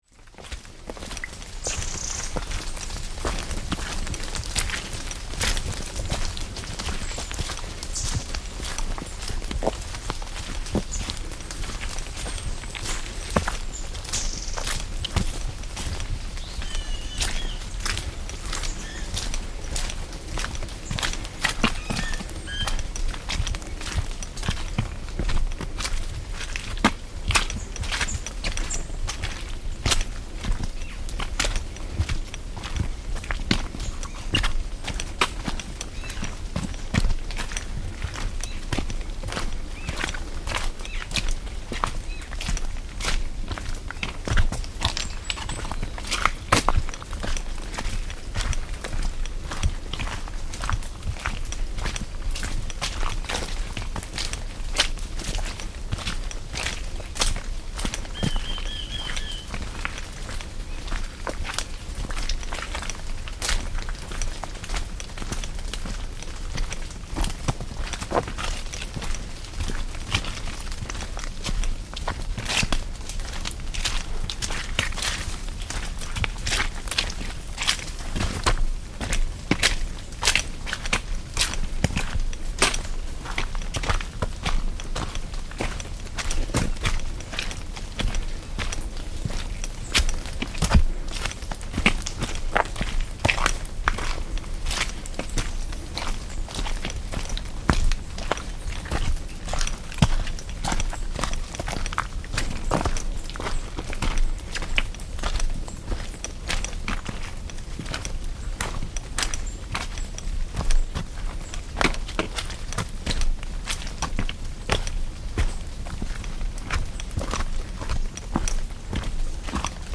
My journey to Machu Picchu was a grueling five-day trek through some of the most challenging yet breathtaking terrain in the Andes. Descending from the high mountains into the lush jungle, I was surrounded by the rhythmic squelch of muddy paths underfoot, the cheerful chirping of dawn birds, and the distant roar of rivers carving through valleys. This soundscape captures the essence of an epic adventure, blending exertion with awe.